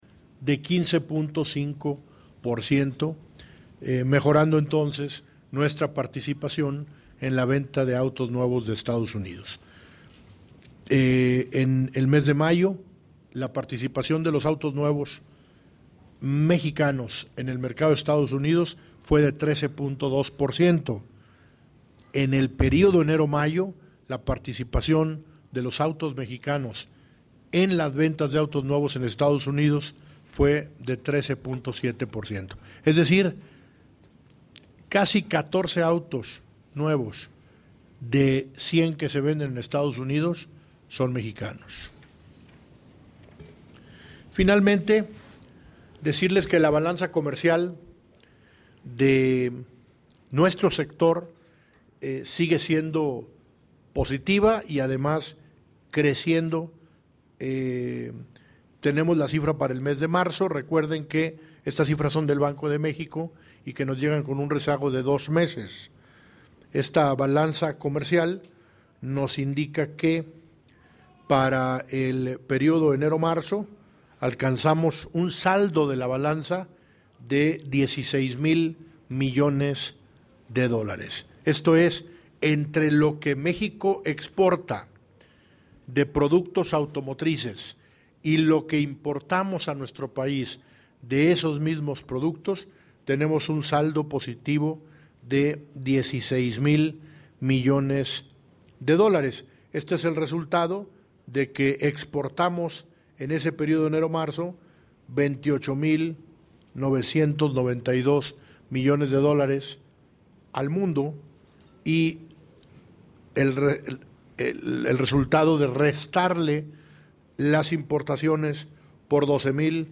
Descarga audio de la conferencia parte 1 aquí